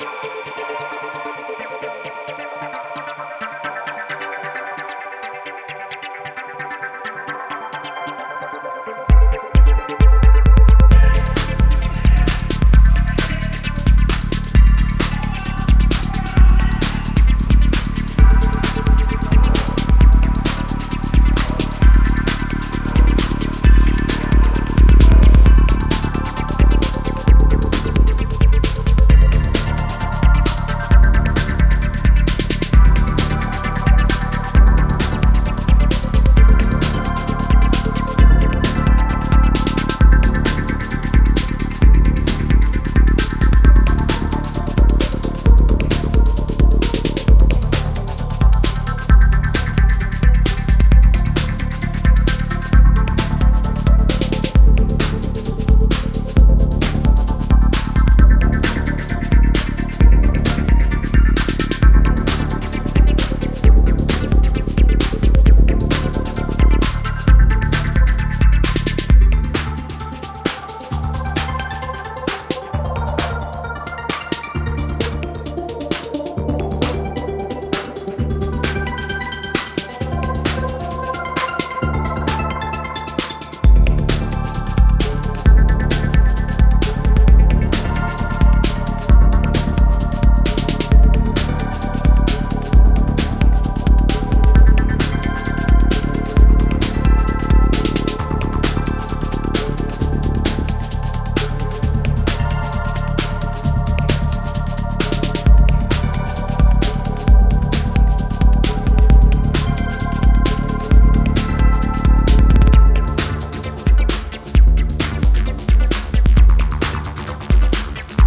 133BPMの美麗エレクトロ